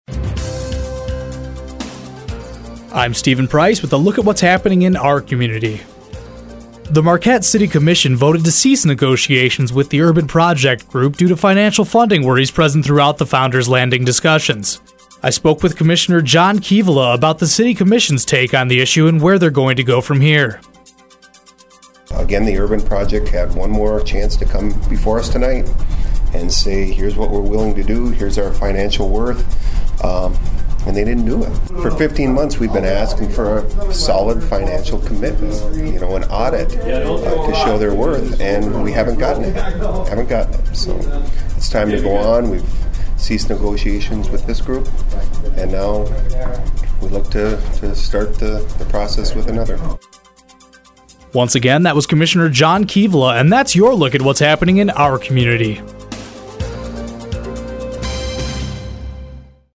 Commissioner John Kivela speaks on why negotiations are over and what happens from here.